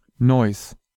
English: Neuss pronounced in German (native speaker)